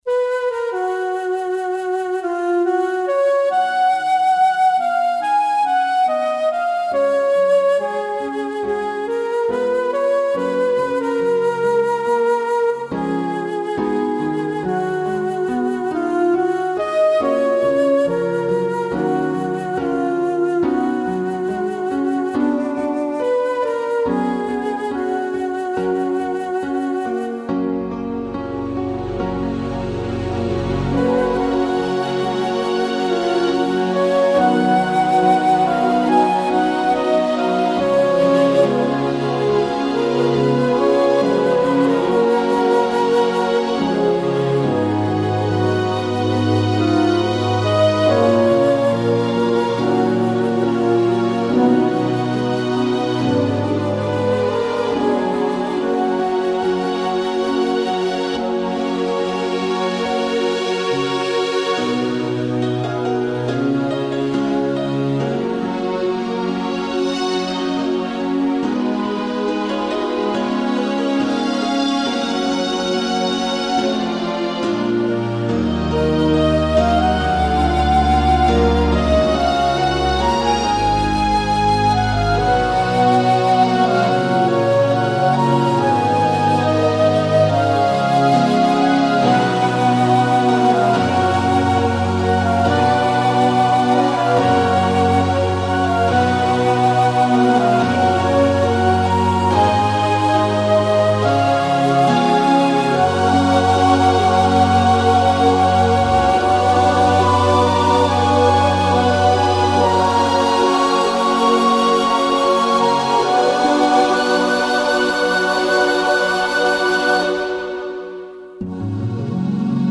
こちらでは、ゲーム内で使われているＢＧＭを何曲か、お聴かせしちゃいます。
タイトルデモにも流れる、作品のイメージ曲です。